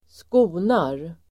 Uttal: [²sk'o:nar]